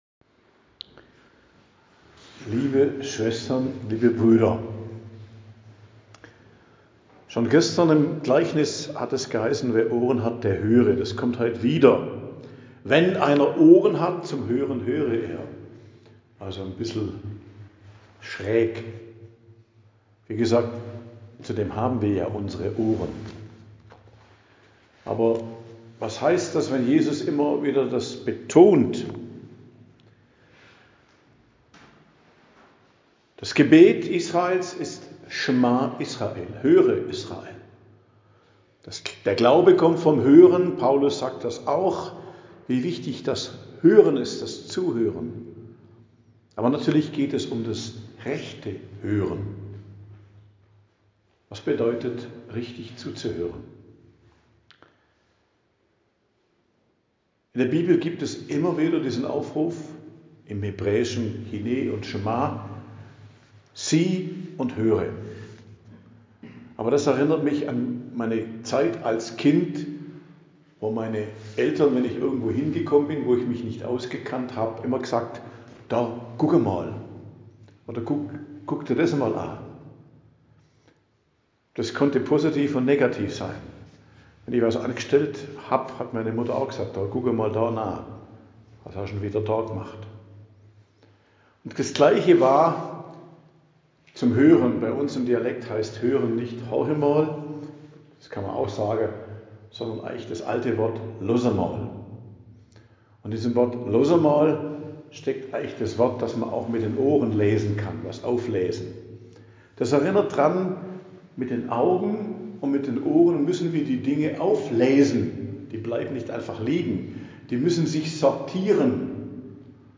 Predigt am Donnerstag der 3. Woche i.J. 30.01.2025